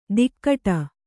♪ dikkaṭa